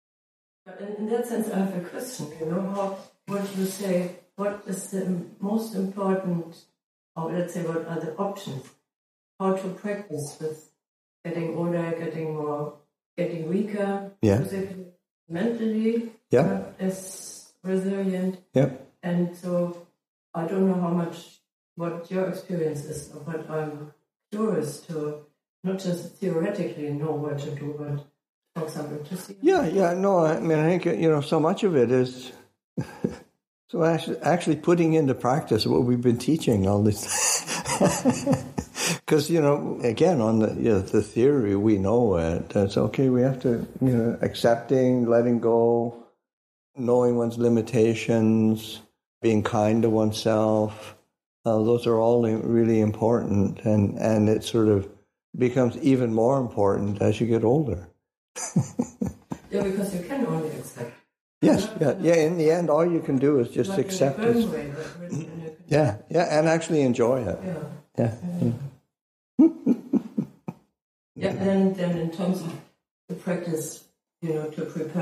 Q&A with the Chithurst Community [2025], Excerpt 1.1